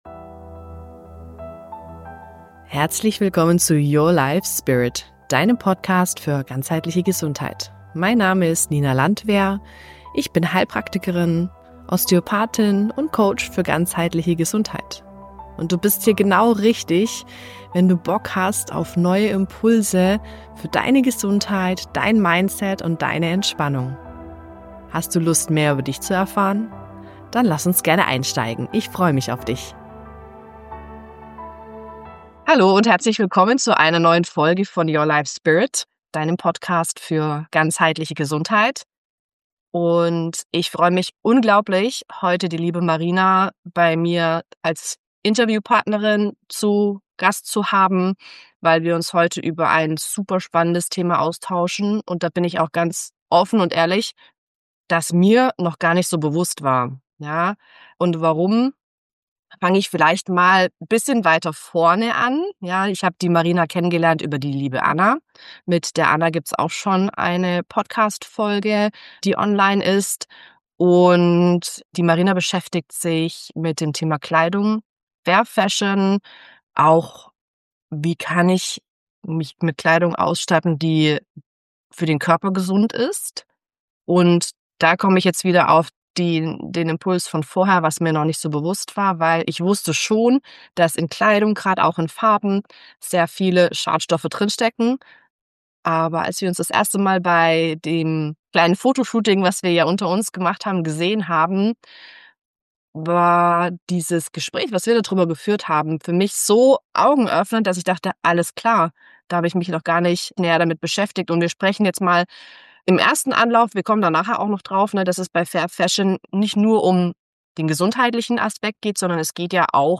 Gemeinsam sprechen wir darüber, was es bedeutet, wenn Du Fast Fashion statt Fair Fashion kaufst: für die Umwelt, für faire Arbeitsbedingungen – vor allem für Frauen und Kinder – und auch für Deine eigene Gesundheit.